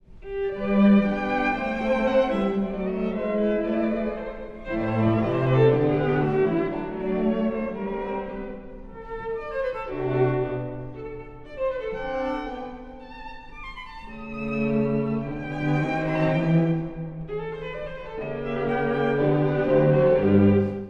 第1楽章…溌剌
（冒頭）　※古い録音のため聴きづらいかもしれません！
「春」の愛称のとおり、快活な楽章です。
第一主題は溌剌としています。